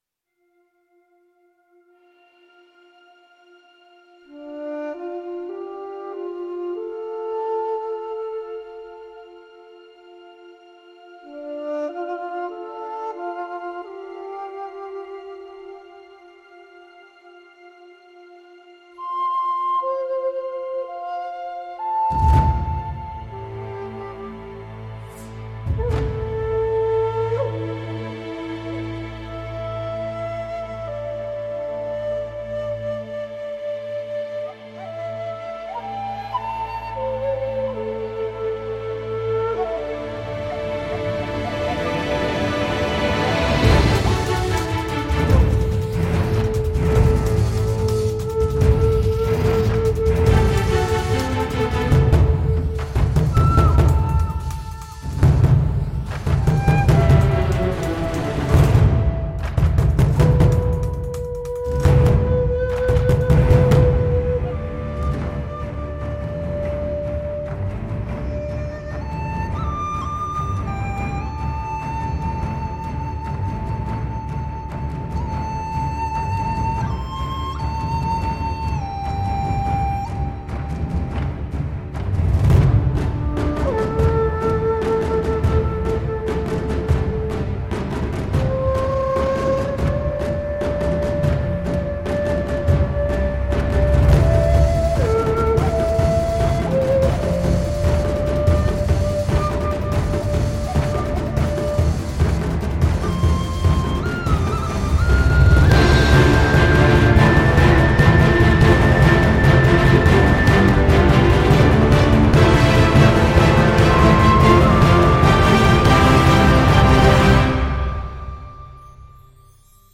这些风笛有着令人难以置信的情感特征，可以为你的音乐增添魔力和灵魂。
这款虚拟乐器包含了 900 多个现场录制的笛子短语，分为 17 个不同的主题。